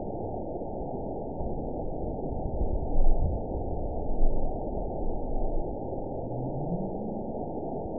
event 912525 date 03/28/22 time 19:37:11 GMT (3 years, 1 month ago) score 8.94 location TSS-AB02 detected by nrw target species NRW annotations +NRW Spectrogram: Frequency (kHz) vs. Time (s) audio not available .wav